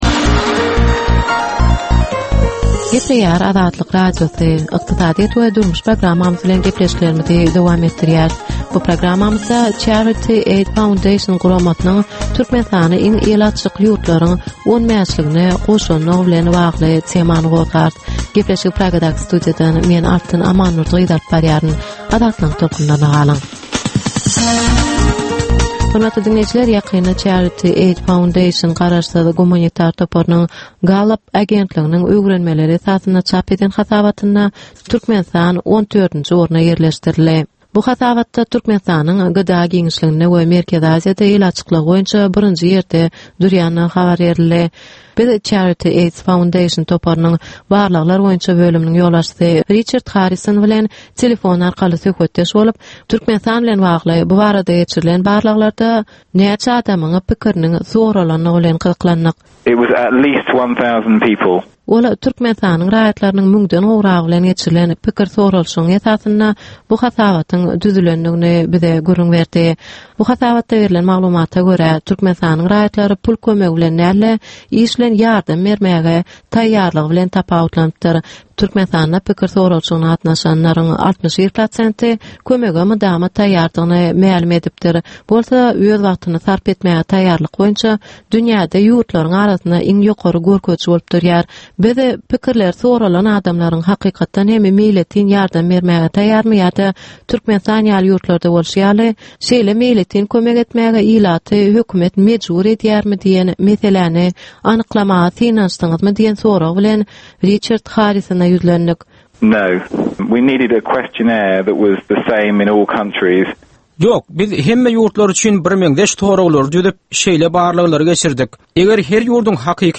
Türkmenistanyň ykdysadyýeti bilen baglanyşykly möhüm meselelere bagyşlanylyp taýýarlanylýan ýörite gepleşik. Bu gepleşikde Türkmenistanyň ykdysadyýeti bilen baglanyşykly, şeýle hem daşary ýurtlaryň tejribeleri bilen baglanyşykly derwaýys meseleler boýnça dürli maglumatlar, synlar, adaty dinleýjileriň, synçylaryň we bilermenleriň pikirleri, teklipleri berilýär.